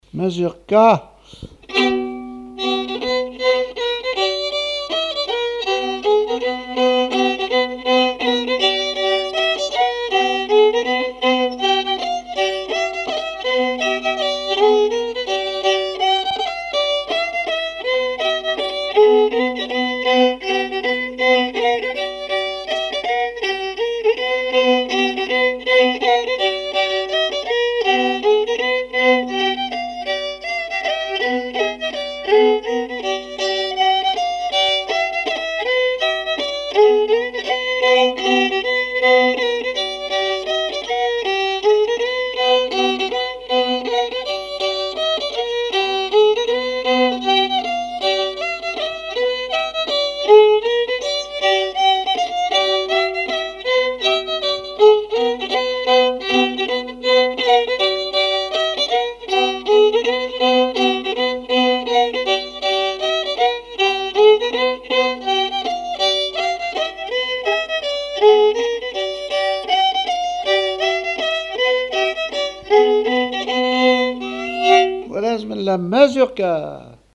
Mémoires et Patrimoines vivants - RaddO est une base de données d'archives iconographiques et sonores.
Mazurka
répertoire musical au violon
Pièce musicale inédite